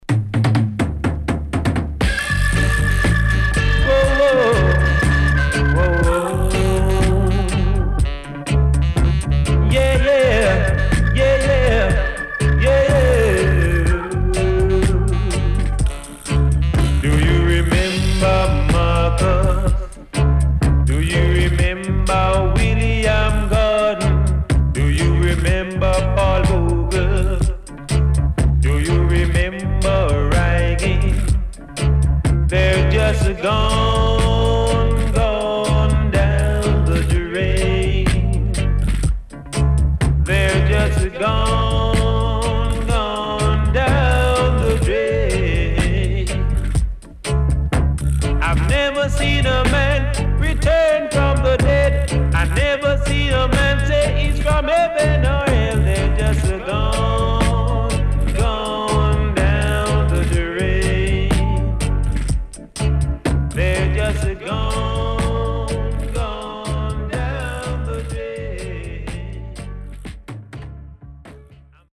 Very Killer Roots.後半Dub接続。W-Side Great